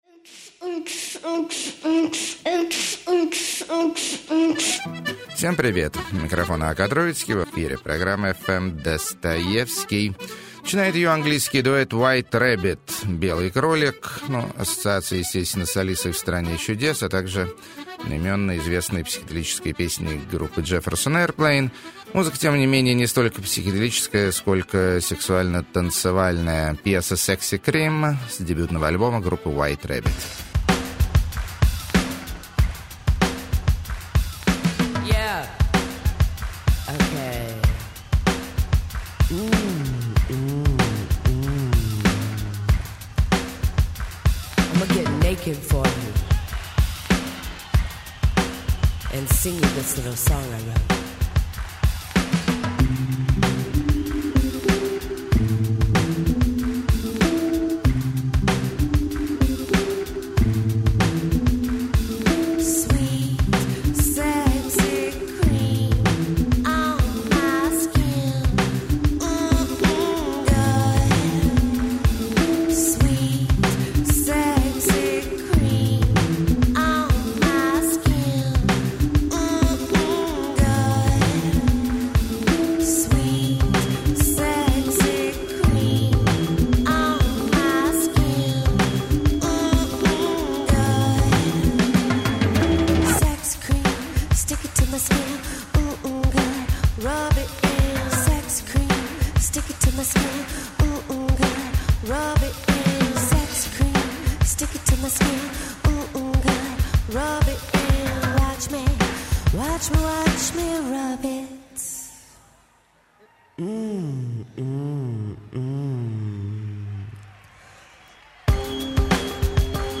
(Basta) [Музыкальная Клоунада Пятидесятых.] 9.
Гитарные Оргазмы.] 13.